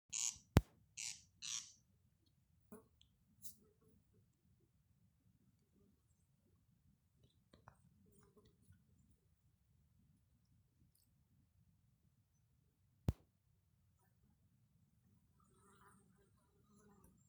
Grieze, Crex crex
StatussDzied ligzdošanai piemērotā biotopā (D)
PiezīmesDzied nenopļautā pļavā blakus Krūmēnu kapiem